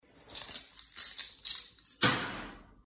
Вытащили ключи и швырнули на стол